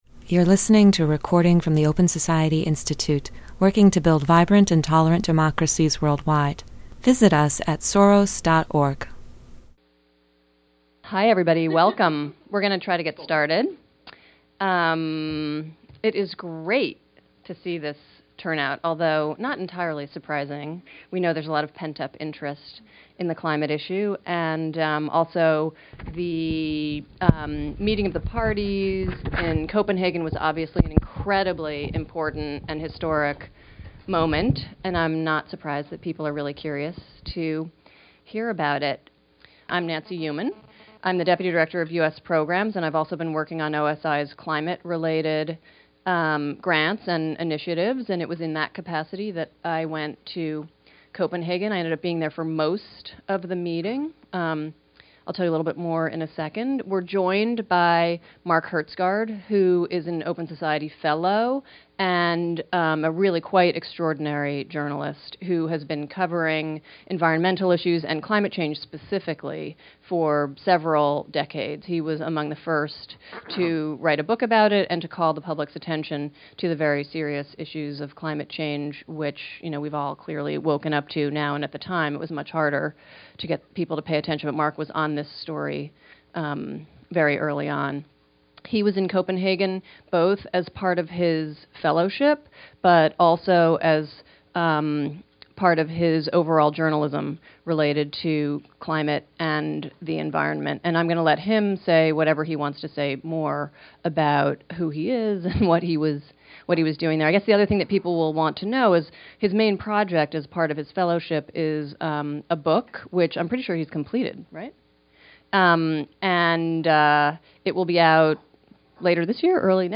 Panelists share their eyewitness observations and assess the gathering’s failure to establish ambitious goals for reducing carbon emissions.